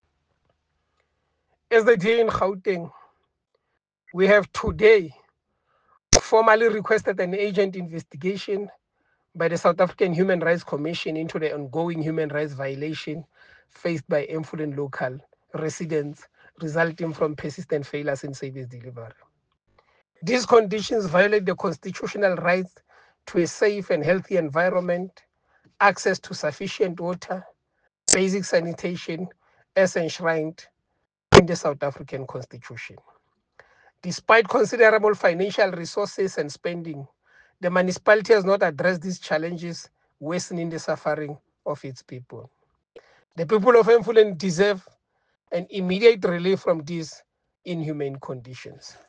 soundbite by Kingsol Chabalala MPL.